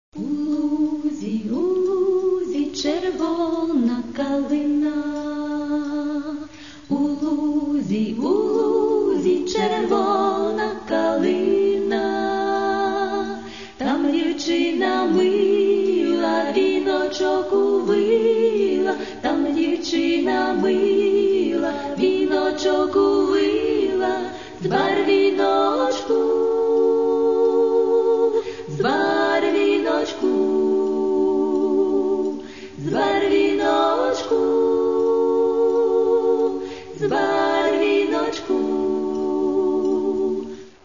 Каталог -> Народна -> Традиційне виконання